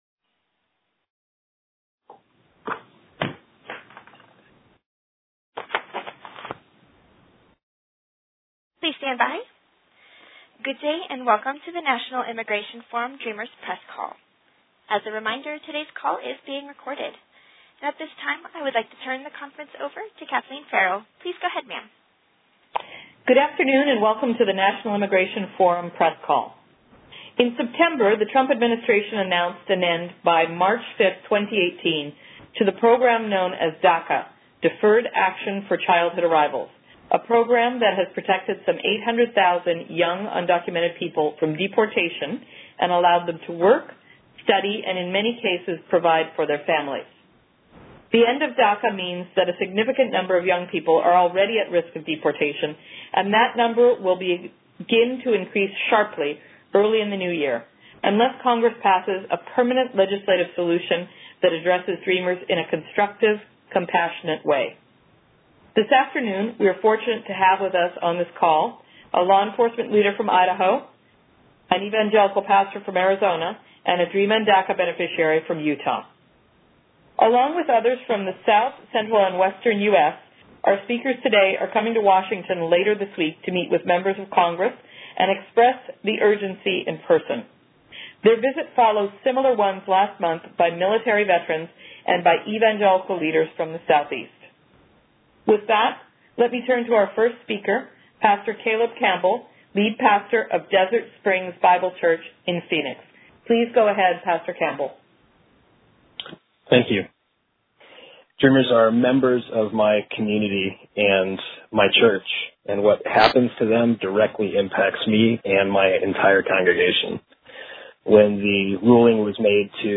Following a press call today on which they discussed the urgency of a solution, these leaders and others from the South Central and Western United States will meet with their members of Congress Wednesday in D.C. to push for legislation.